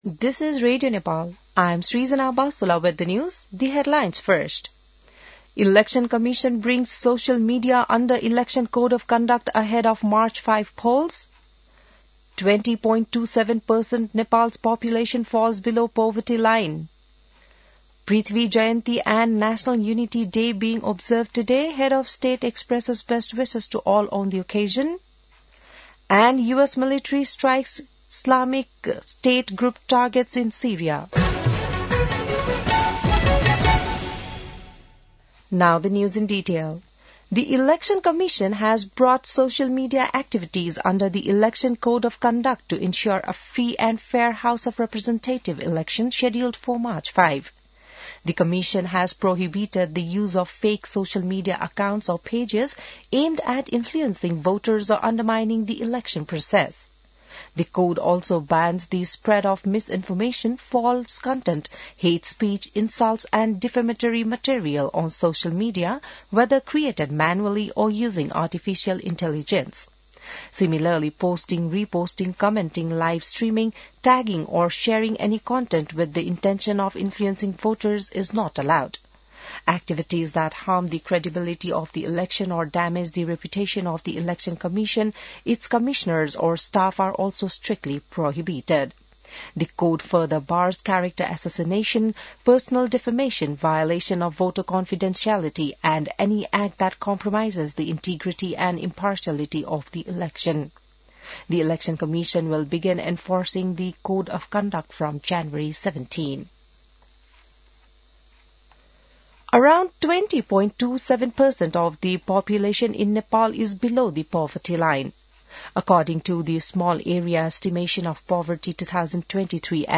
बिहान ८ बजेको अङ्ग्रेजी समाचार : २७ पुष , २०८२